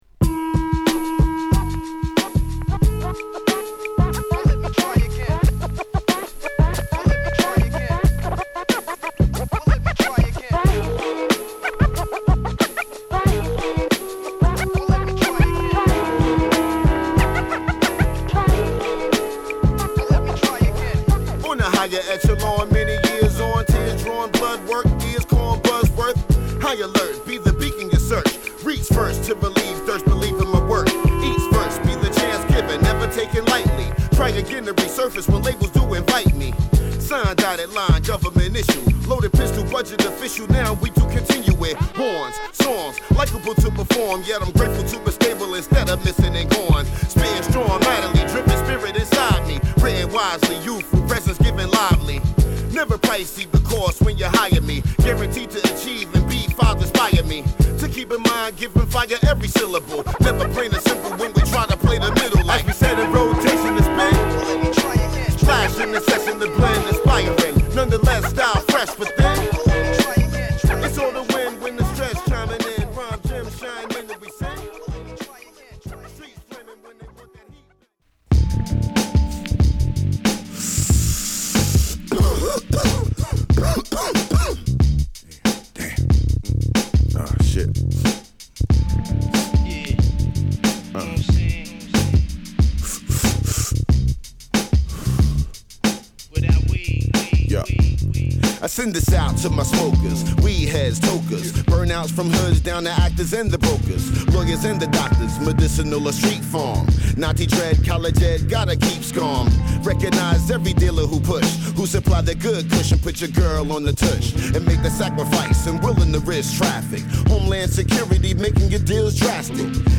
Vocal Version